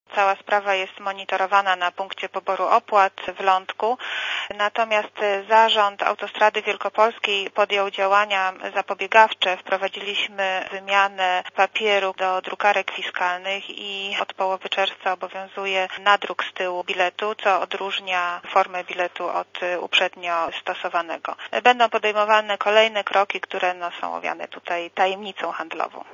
Komentarz audio (104Kb)